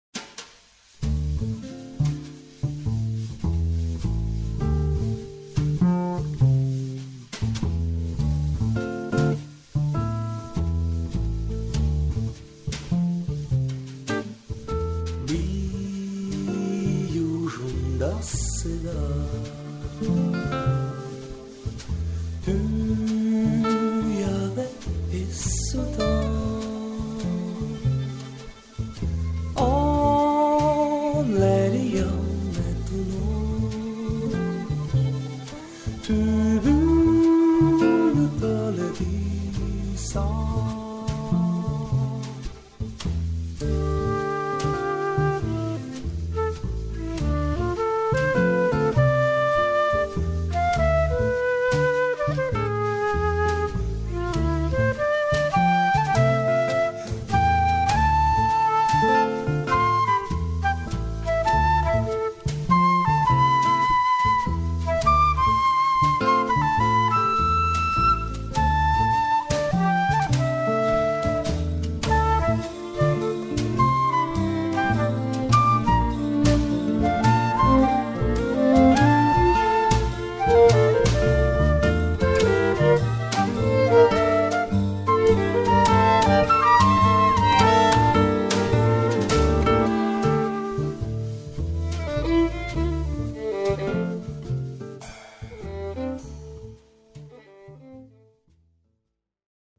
glas, akustična kitara
sopranski saksofon, flavta
violina
viola, violina
kontrabas
tolkala